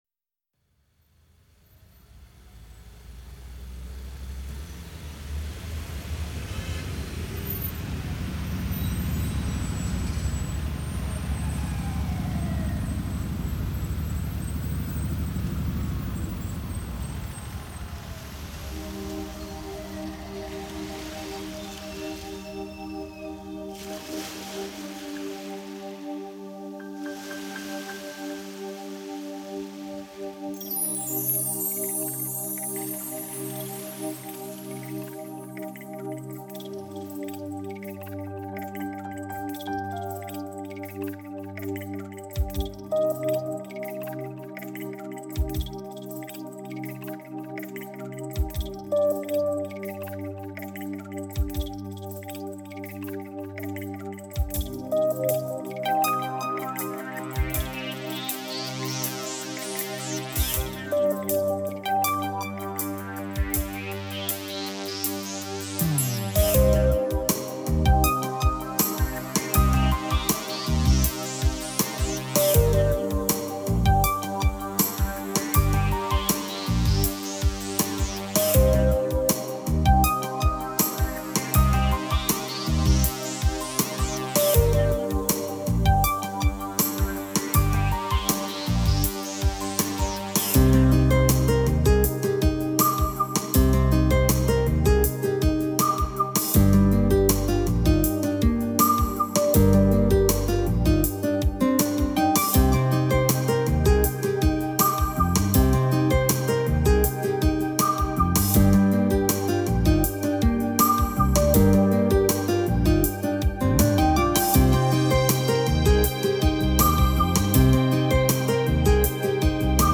Жанр: Ambient.